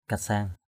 /ka-sa:ŋ/ (d.) gông = cangue. cangue. buh kasang b~H kx/ đóng gông, mang gông.